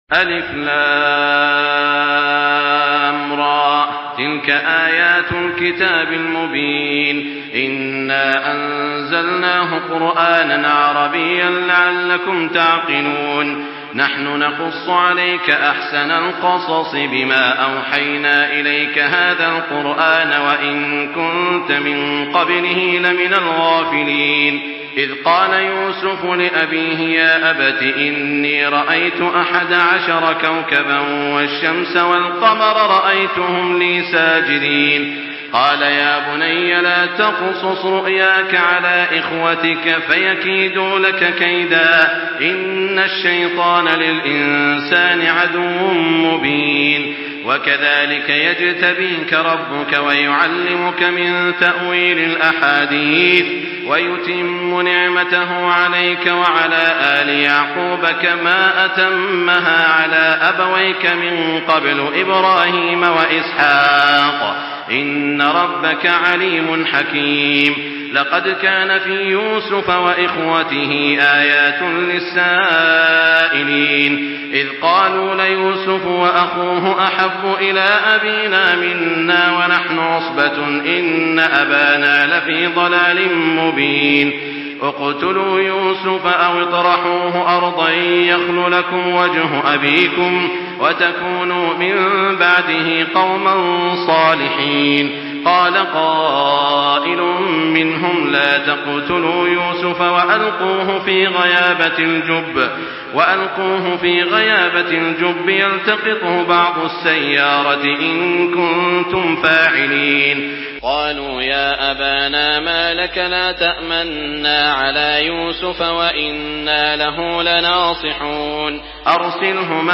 تحميل سورة يوسف بصوت تراويح الحرم المكي 1424
مرتل